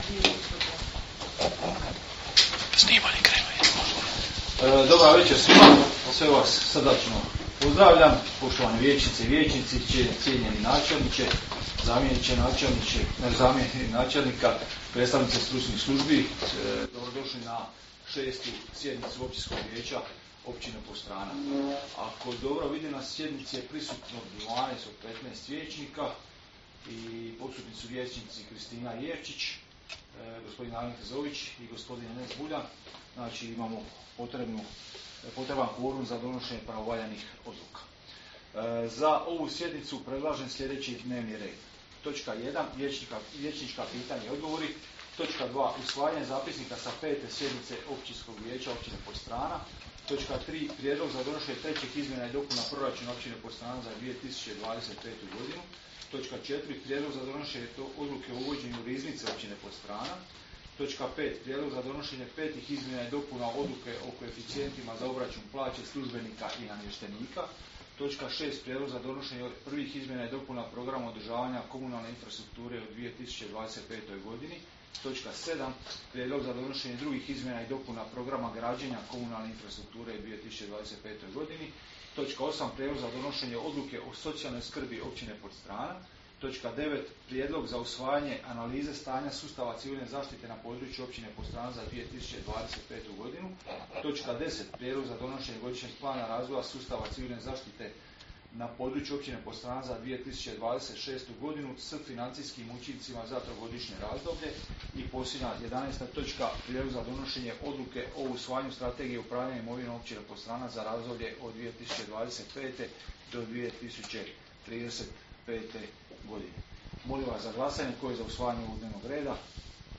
Sjednica će se održati dana 22. prosinca (ponedjeljak) 2025. godine u 19,00 sati u Vijećnici Općine Podstrana.